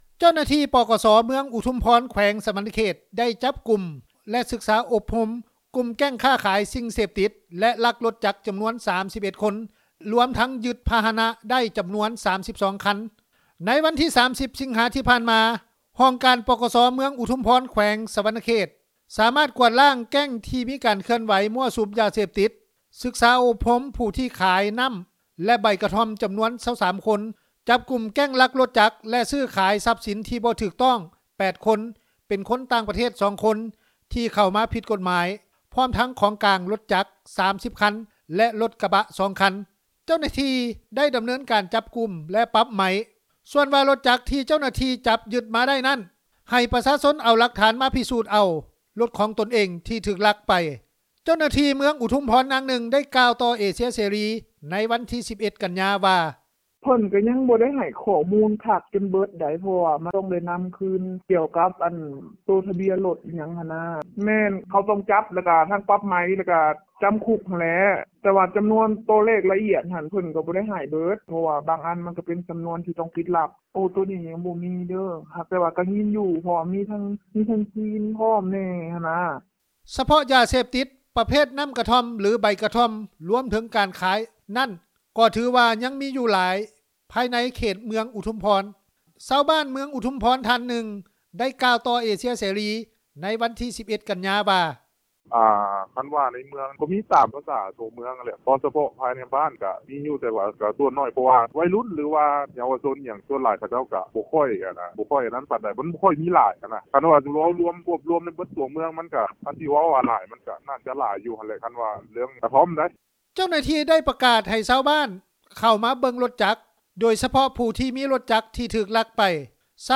ຊາວບ້ານເມືອງອຸທຸມພອນ ທ່ານນຶ່ງ ໄດ້ກ່າວຕໍ່ເອເຊັຽເສຣີ ໃນວັນທີ 11 ກັນຍາ ວ່າ:
ຊາວເມືອງອຸທຸມພອນ ອີກທ່ານນຶ່ງ ໄດ້ກ່າວວ່າ: